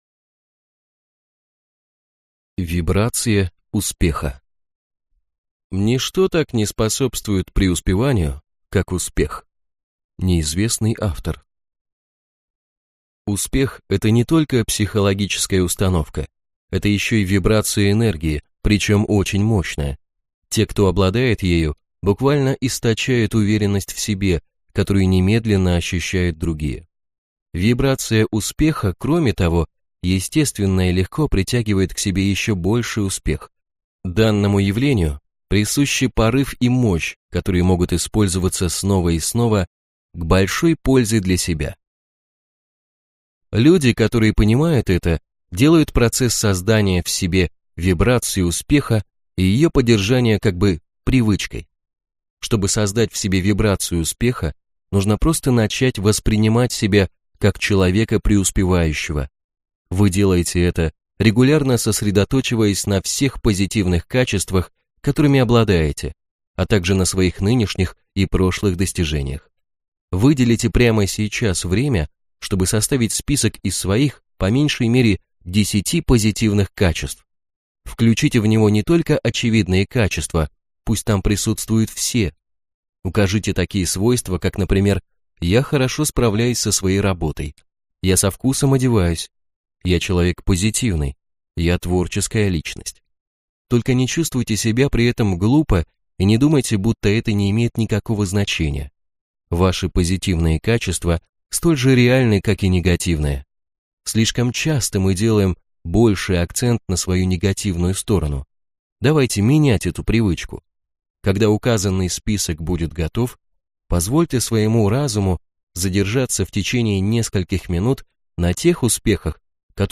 Аудиокнига Деньги, успех и вы | Библиотека аудиокниг